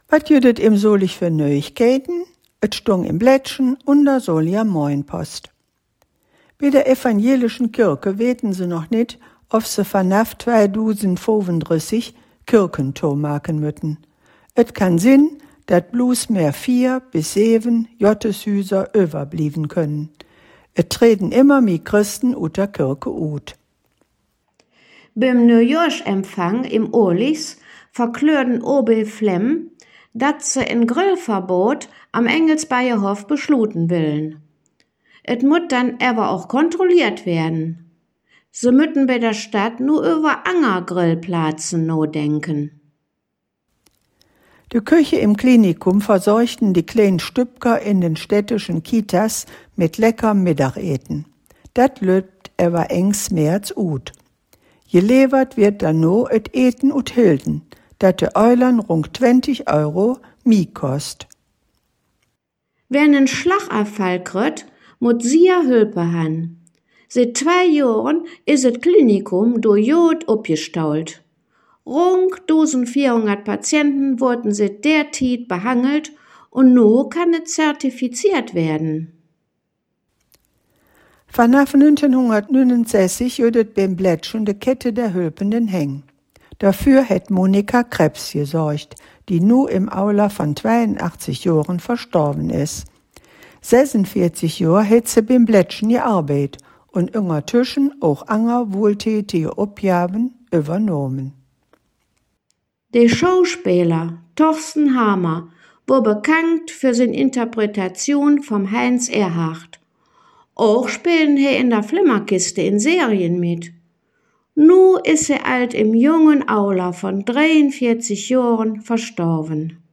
Der in Solingen gesprochene Dialekt wird Solinger Platt genannt.
Zugleich wird im Solinger Platt aber auch die Nähe zum ripuarischen Sprachraum (vor allem durch das Kölsch bekannt) hörbar.